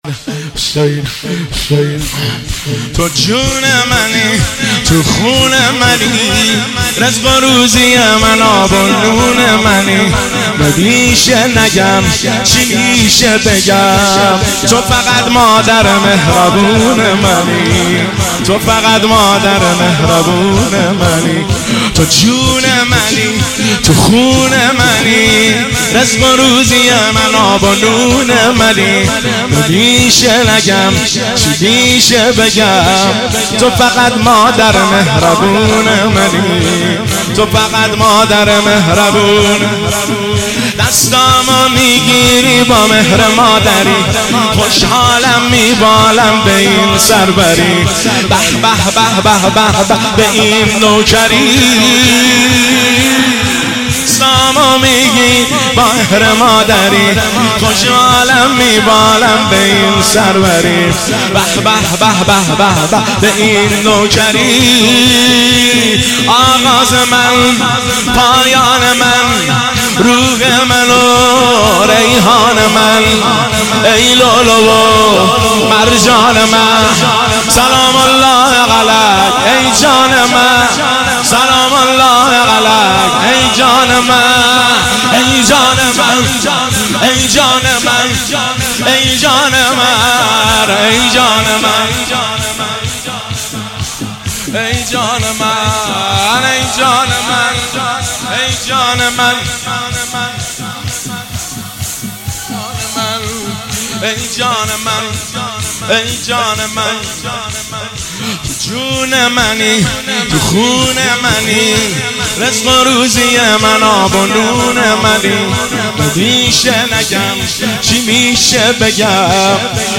تو جون منی ، توو جون منی شور – شب اول فاطمیه دوم 1402 هیئت بین الحرمین طهران